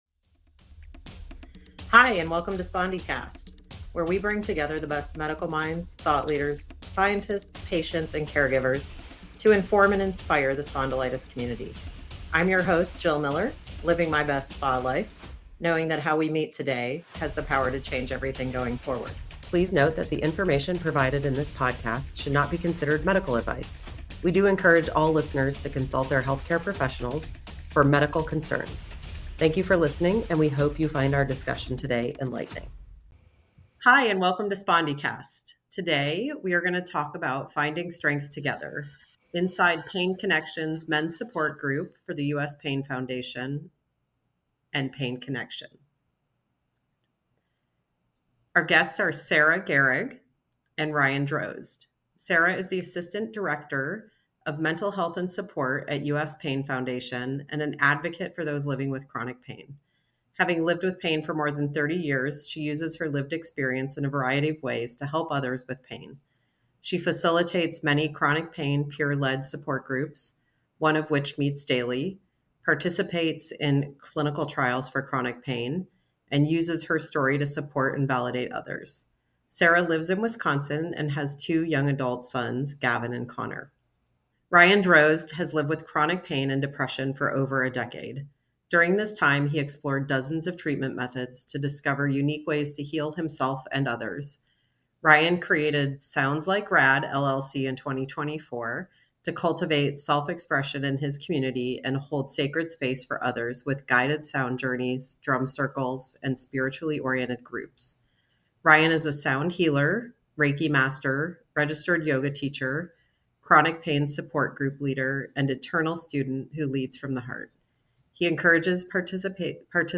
Introducing Spondycast, the newest and most exciting source of information and inspiration for people with spondyloarthritis. Every week, we bring you in-depth conversations with the leading experts in the field, who will share their knowledge and experience on topics related to the diagnosis, treatment, and management of this complex condition.